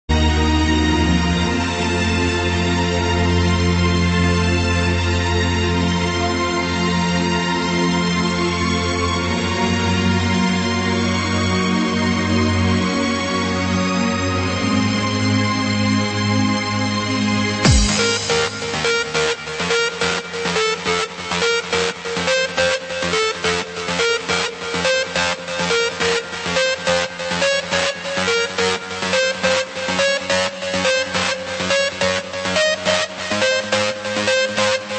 • Classical Ringtones